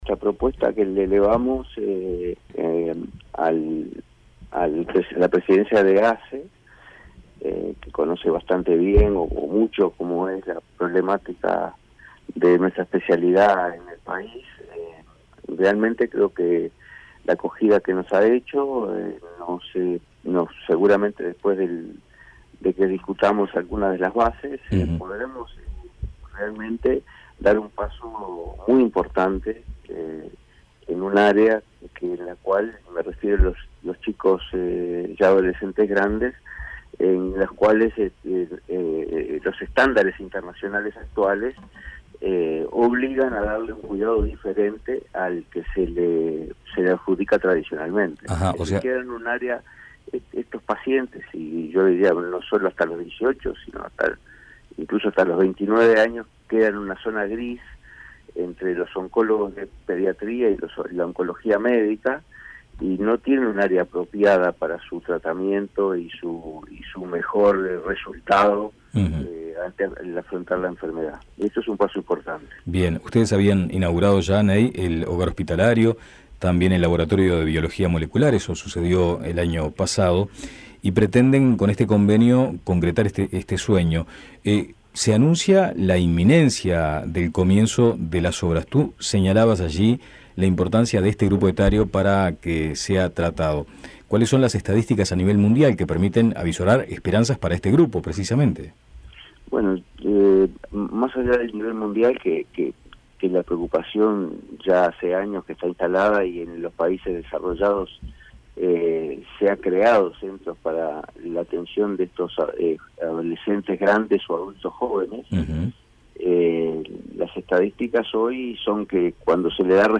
habló en Índice 810 sobre la firma de las bases de un acuerdo entre ASSE y la fundación que permitirá aumentar la atención de adolescentes y jóvenes con cáncer.<